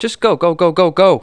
1 channel
SCI-BUMPING2.WAV